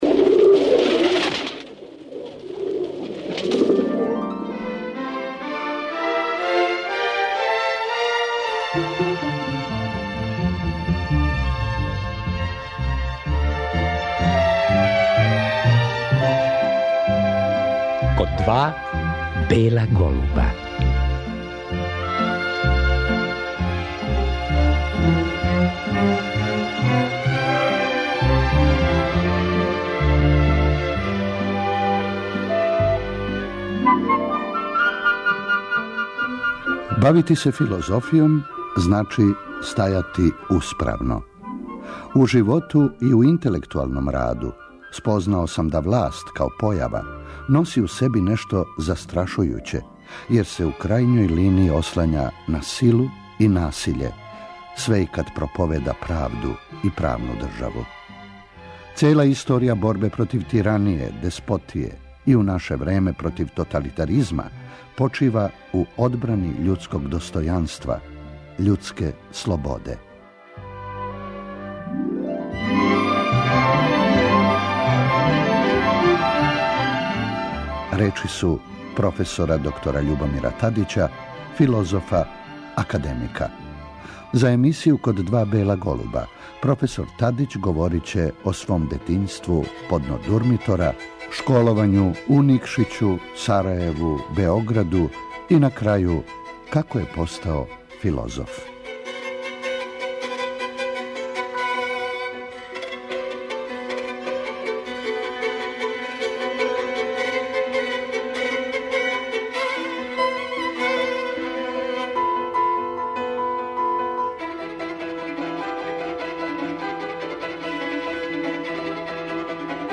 На вест да је преминуо професор филозофије Љубомир Љуба Тадић, стални члан САНУ и један од интелектуалаца који су учествовали у обнови Демократске странке 1989. године, слушаћемо емисију из 2000. године у којој је гост био професор Тадић.